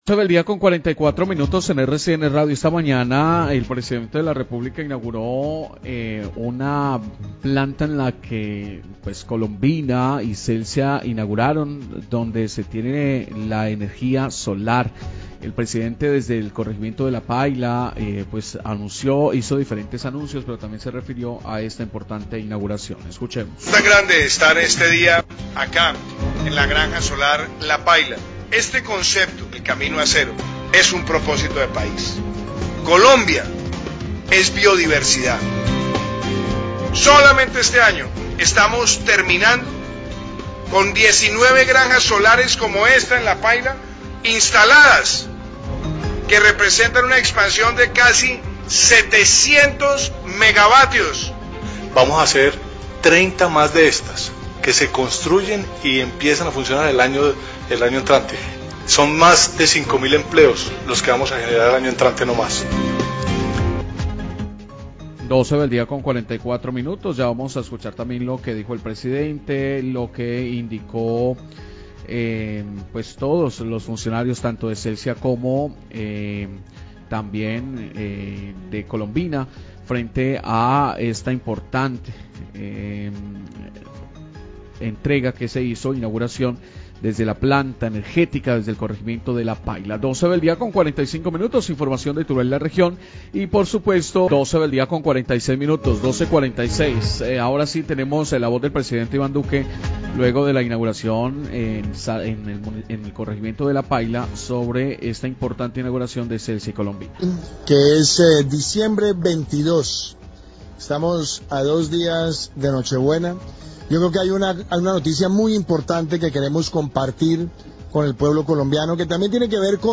El presidente Iván Duque estuvo en la inauguración de una granja solar de Celsia en el corregimiento de La Paila en Zarzal. (Palabras del pdte Duque..... pero se corta el audio. Intentan de nuevo y vuelve y falla; el periodista afirma que mañana intentarán de nuevo emitir las palabras del primer mandatario)